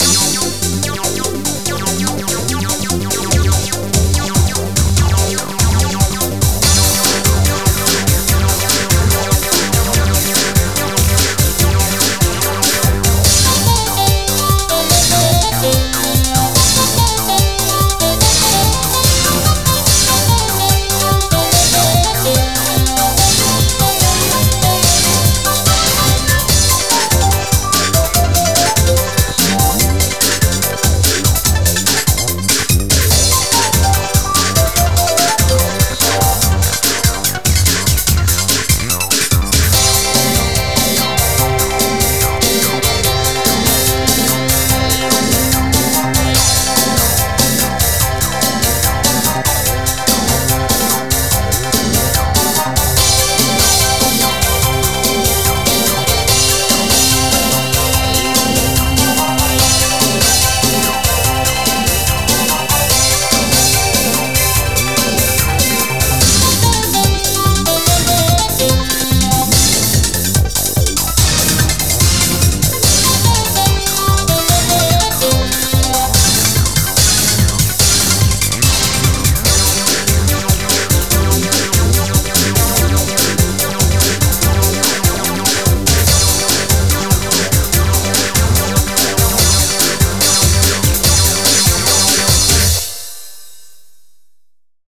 BPM145
Better quality audio.